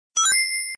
короткие